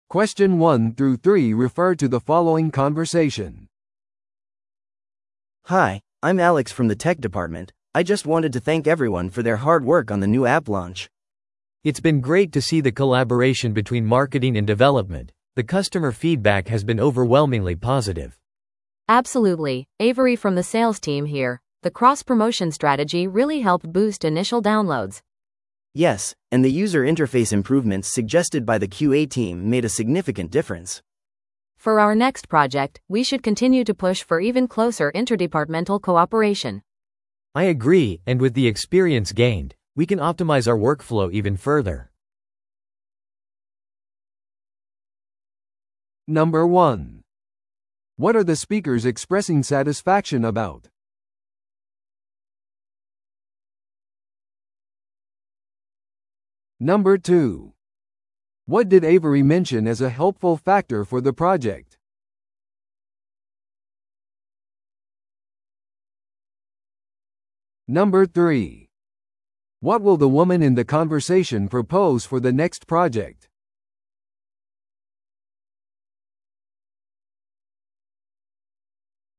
TOEICⓇ対策 Part 3｜チーム間の協力に対する褒め言葉 – 音声付き No.296